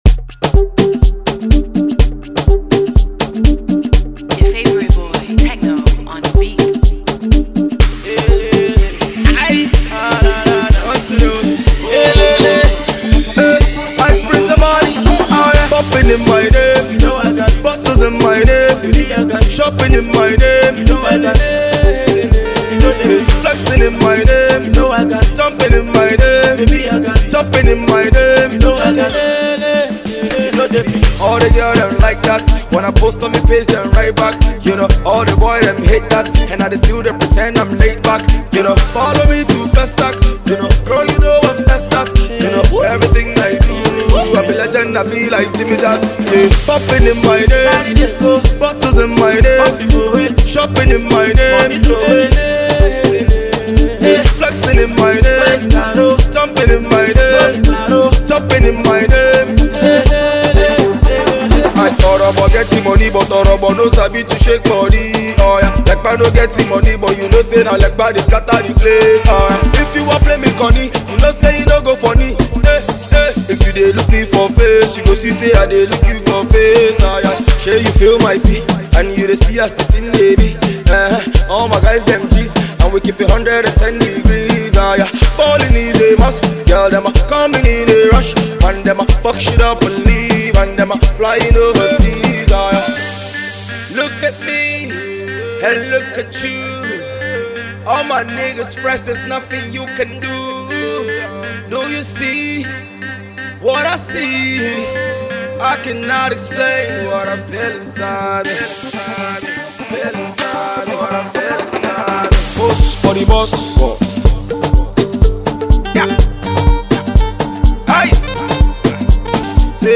has a groovy vibe to it